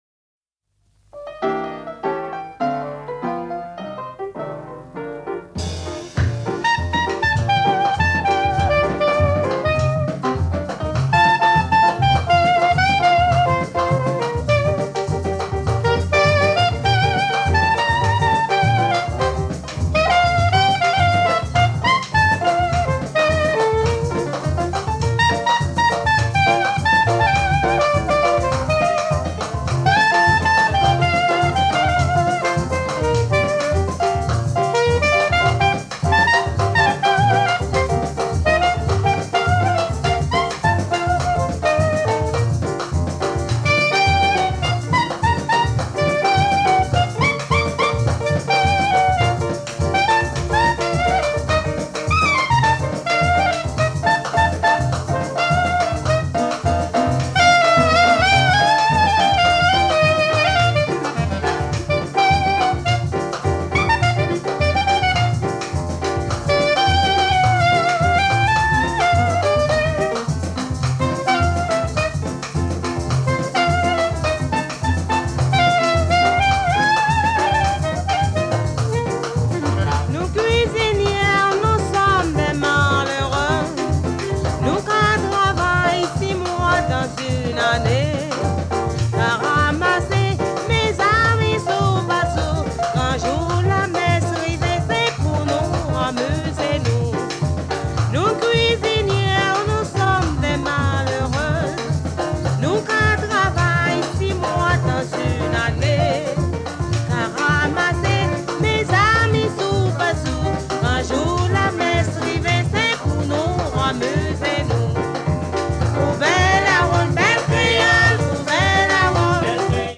カリブ海のダンスミュージック、“ビギン”屈指の名盤！極上のフレンチ・カリビアン・ミュージック！
スウィングした小気味よいリズムがとにかく心地良く
哀愁とモダンを兼ね揃えた名盤！